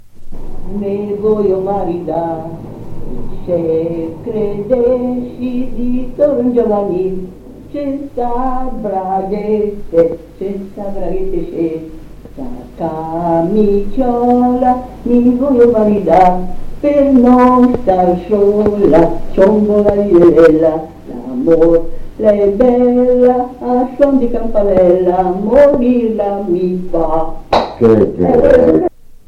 Post 1975. 1 bobina di nastro magnetico.